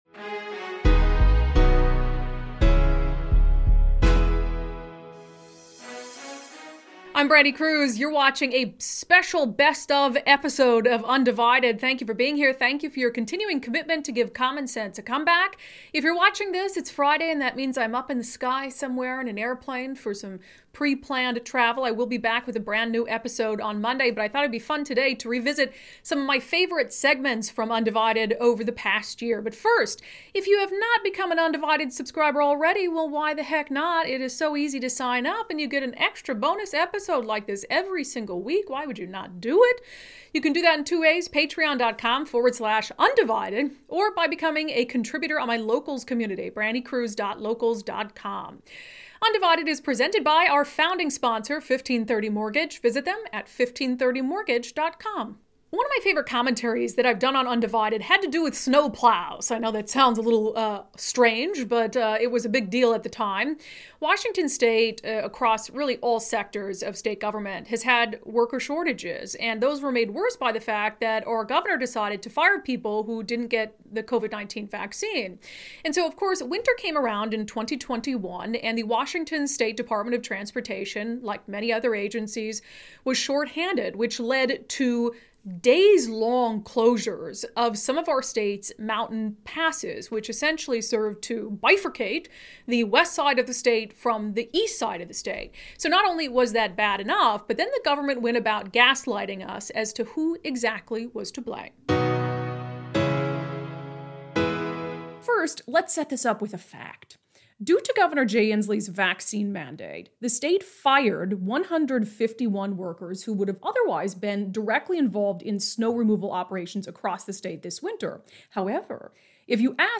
featuring some of her favorite interviews and segments from [un]Divided.